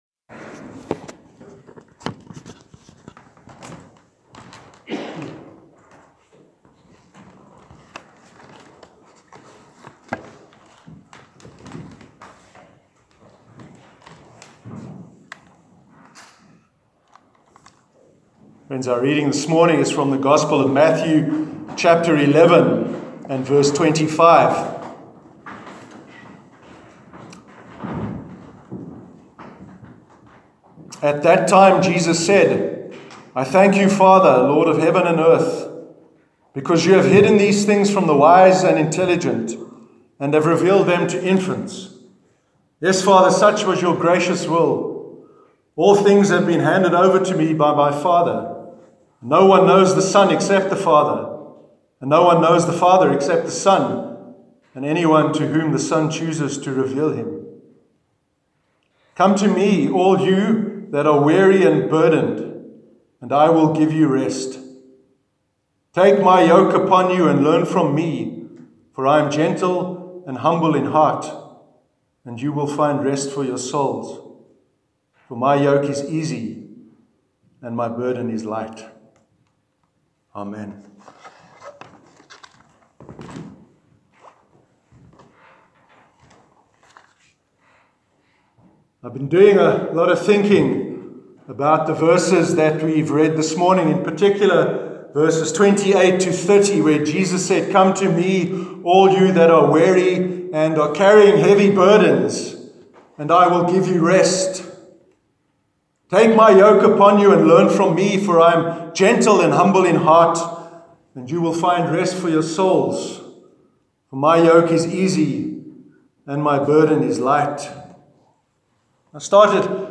Sermon on the Yoke of Jesus- 15th July 2018